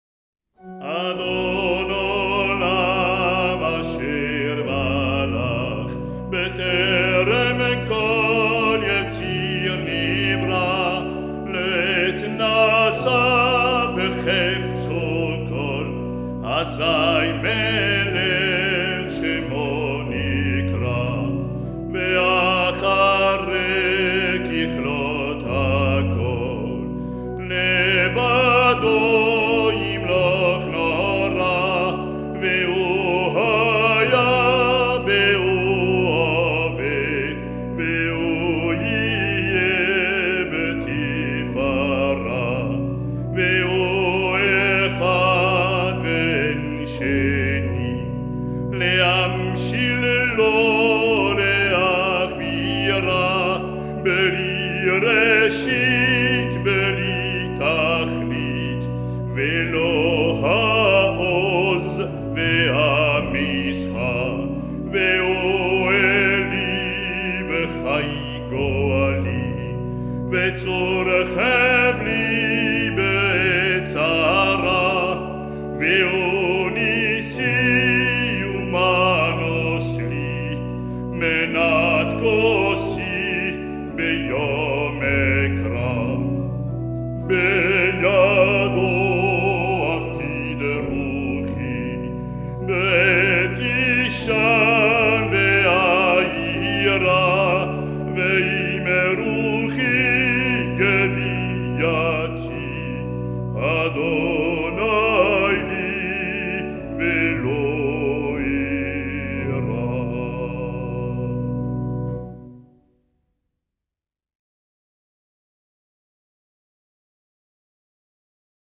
« Adon Olam » est un poème liturgique chanté à l’office du matin.